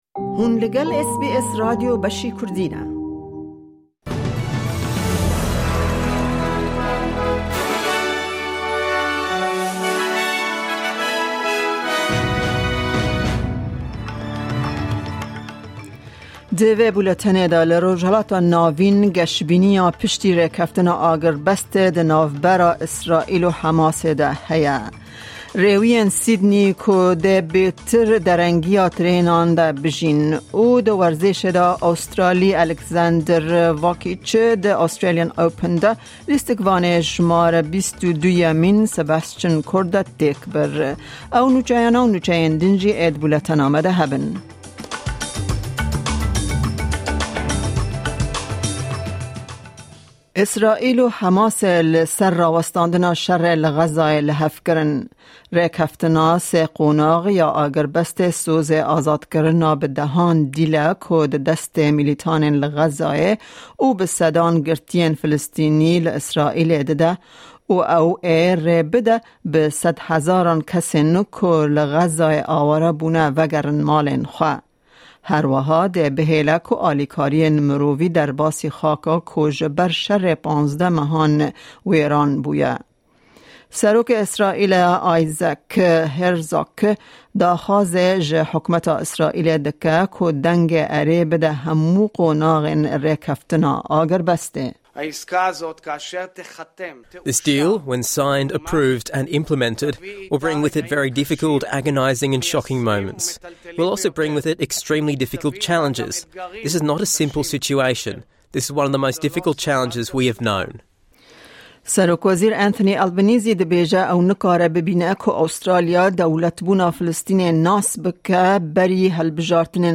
Nûçeyên roja Pêncşemê, 26î Çileya 2025